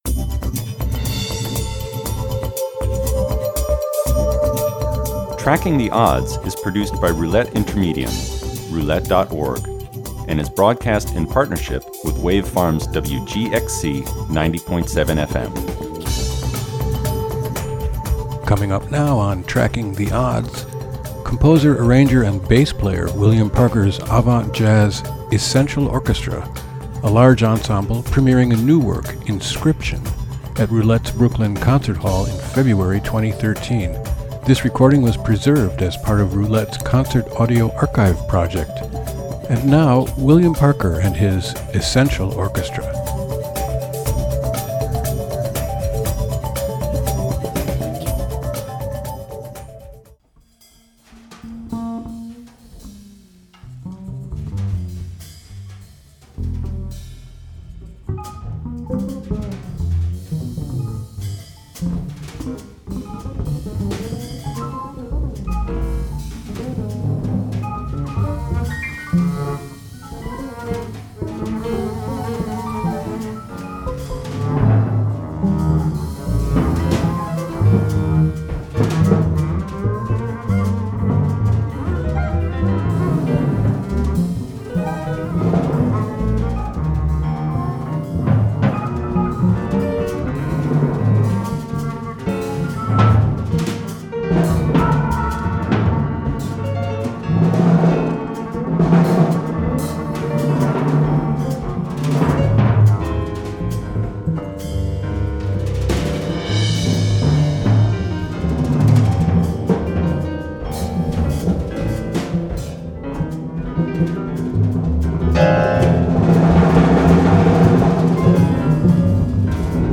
large ensemble work
avant-garde scene in jazz
alto sax
trumpet
trombone
tenor sax
baritone sax
tuba, bass
piano
electric keyboard
drums
vocals